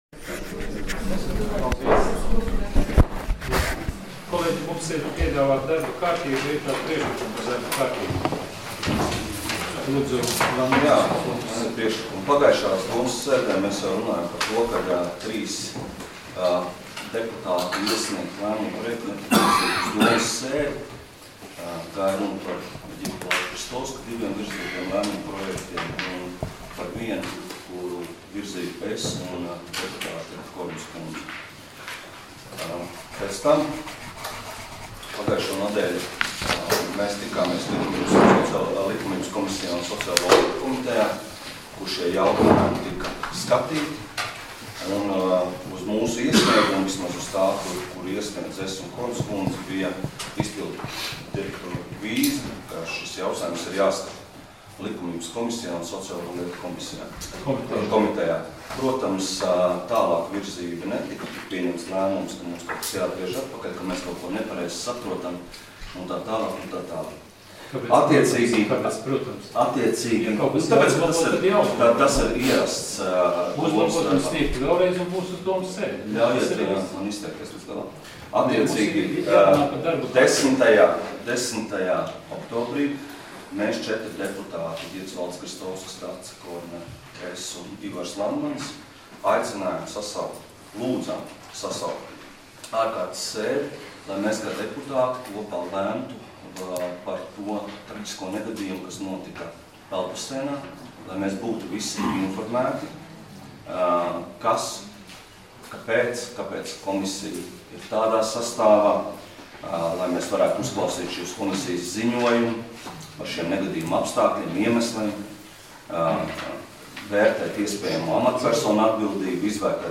Domes sēdes 19.10.2017. audioieraksts